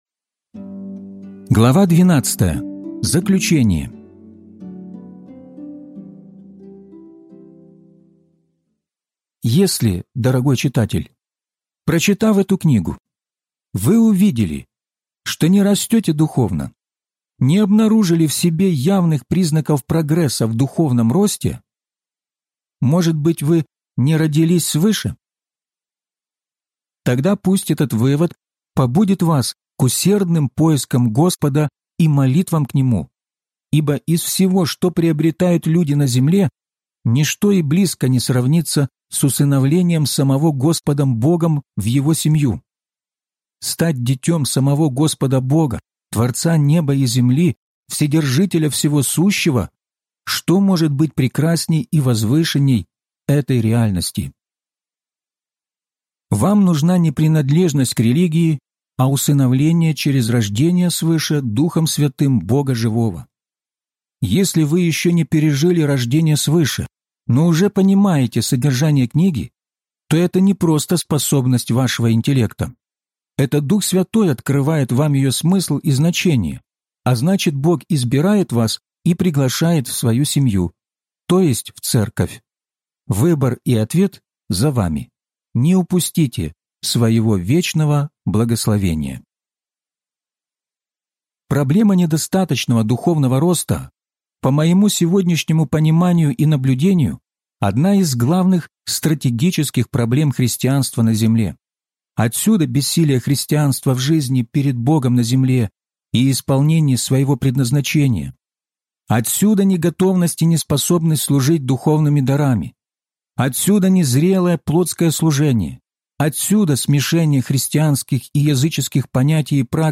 Возрастайте! (аудиокнига)